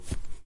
恶魔之刃声音效果 " FX 001脚步声草L
描述：草表面上的唯一脚步